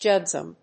ジー‐エムディー‐エス‐エス